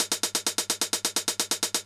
CLF Beat - Mix 10.wav